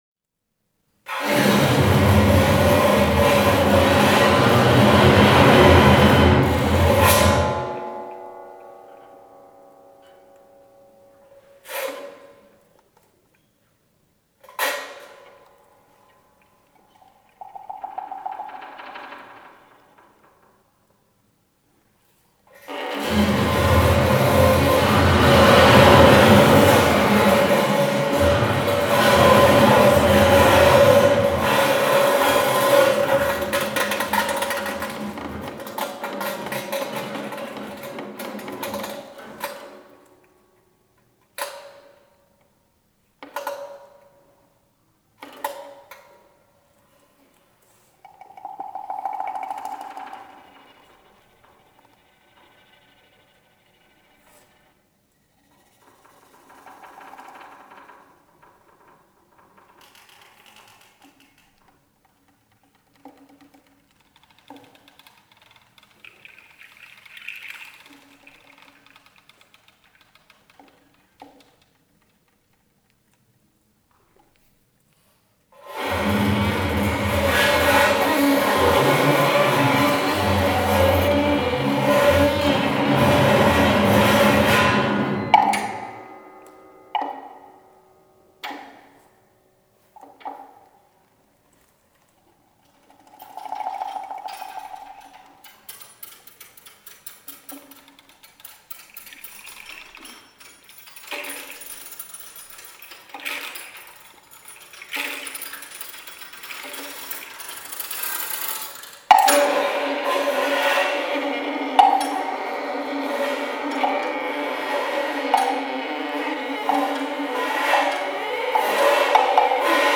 per vl. pf. e perc.